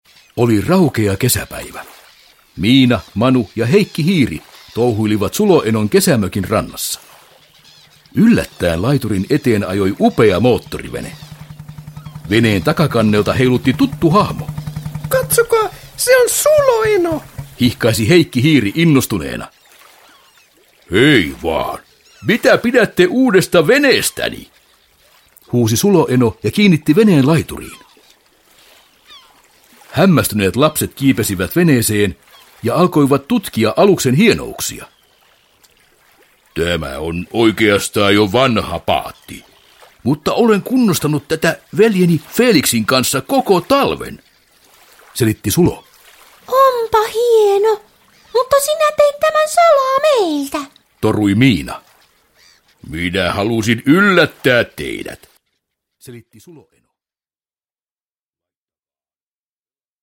Miina ja Manu Seikkailu enon veneellä – Ljudbok – Laddas ner